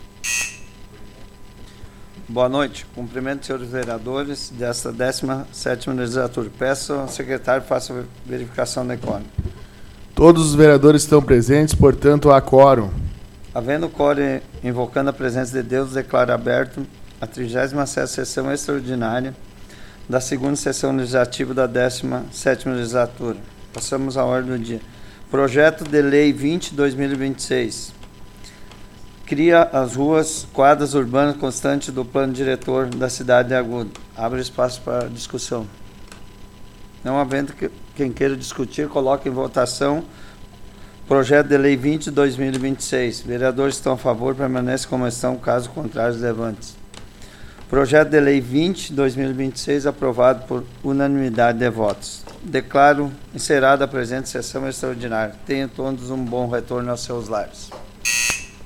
Áudio da 38ªSessão Plenária Extraordinária da 17ª Legislatura, de 02 de março de 2026